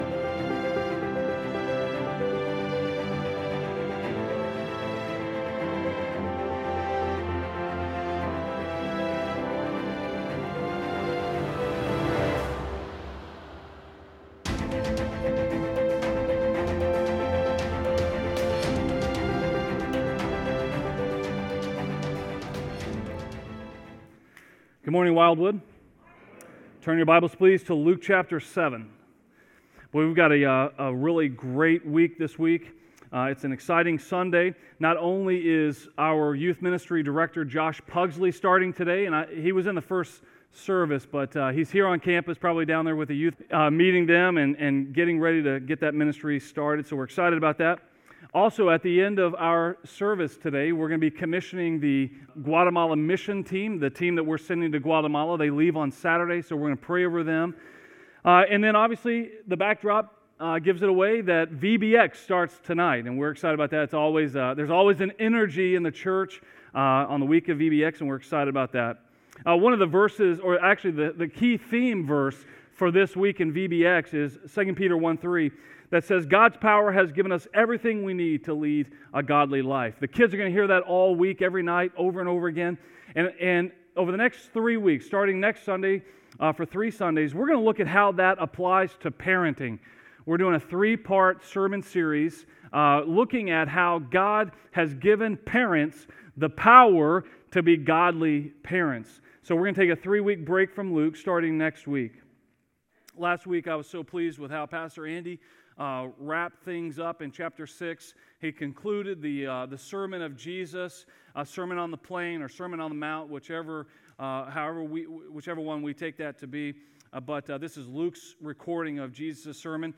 Sermon 7-14-19 from Wildwood Church on Vimeo.